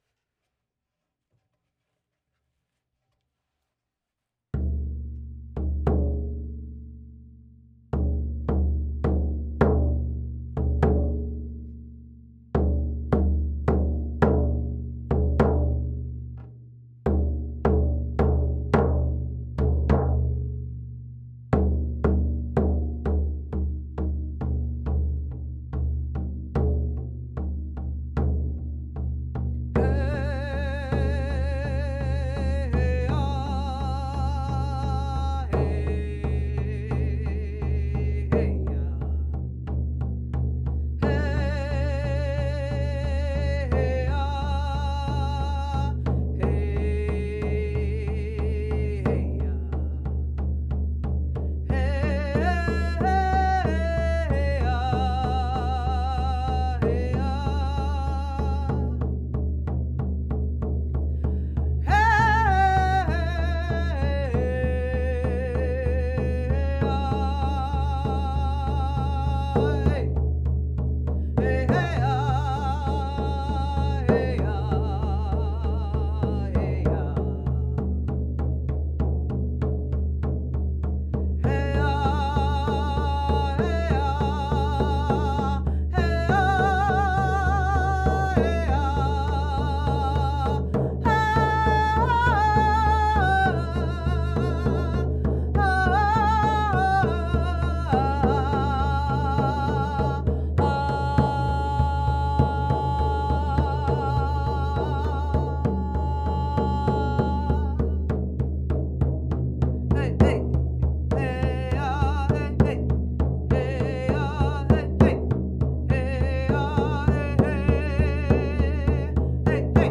Drum Journey- Heart